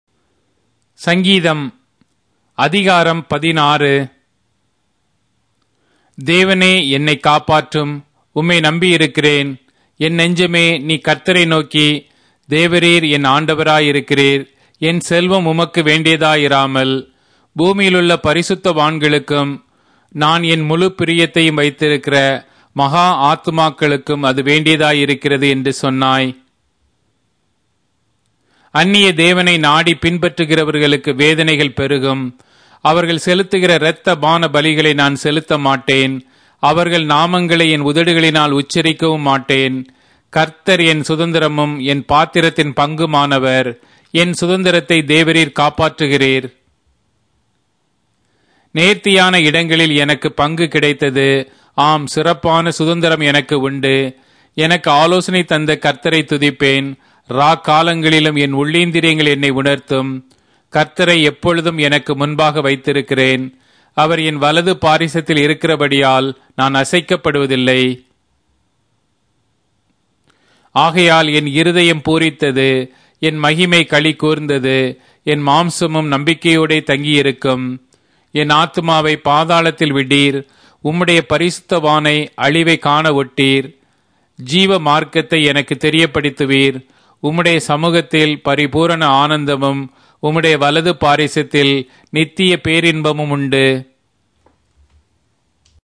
Tamil Audio Bible - Psalms 21 in Litv bible version